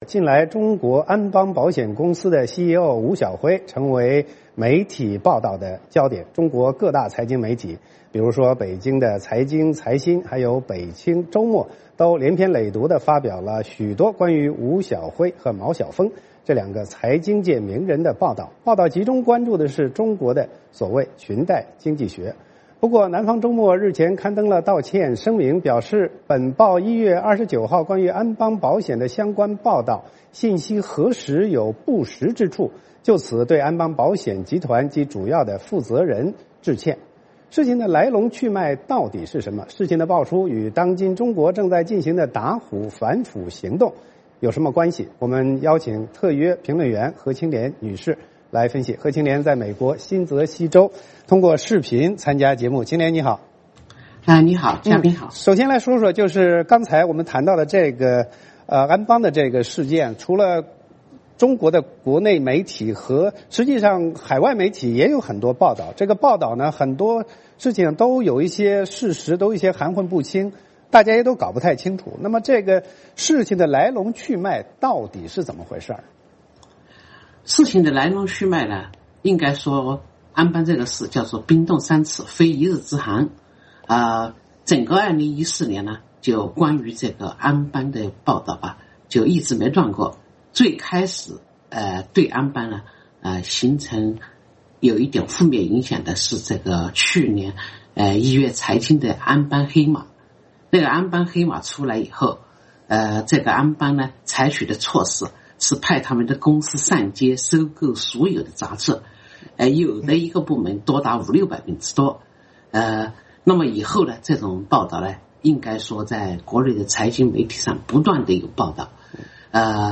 事情的曝出与当今中国正在进行的“打虎”反腐行动有什么关系？我们邀请了特约评论员何清涟女士来分析。